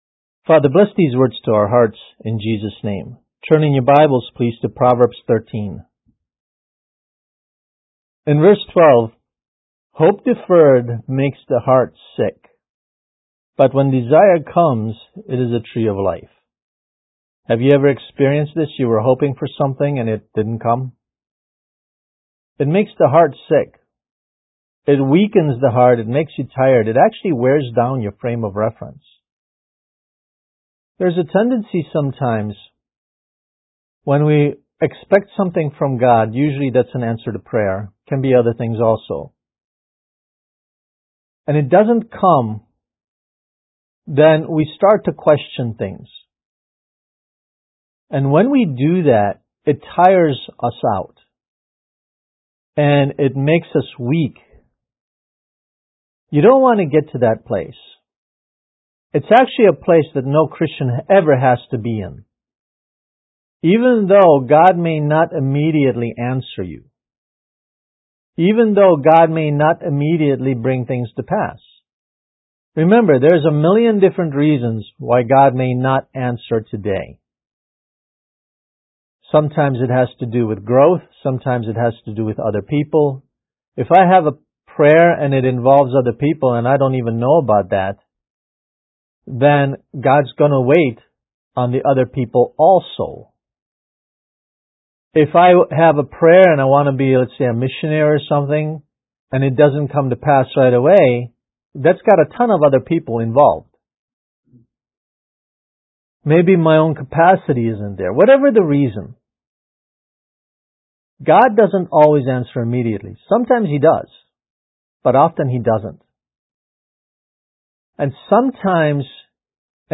Kids Message: Protect Your Confident Expectation